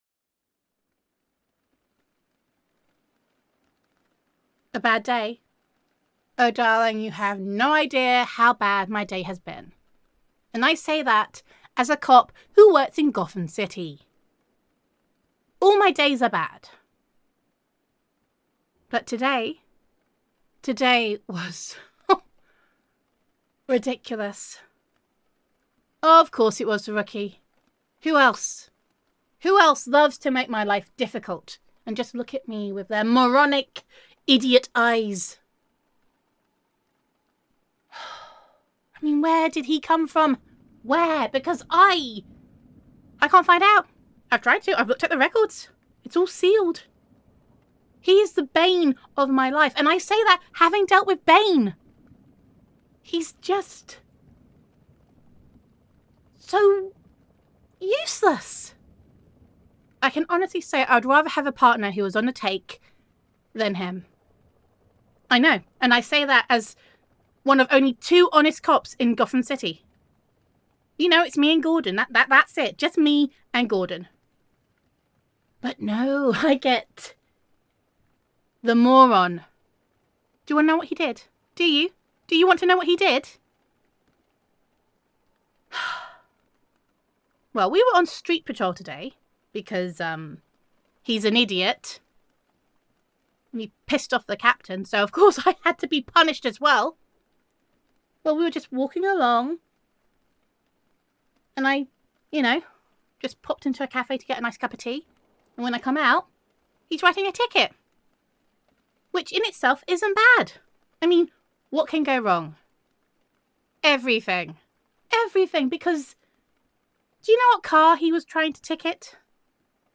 [F4A] That Damn Rookie [Detective Roleplay][Girlfriend Roleplay][Gotham City][Batman][Gender Neutral][It Is Tough Being an Honest Cop in Gotham City]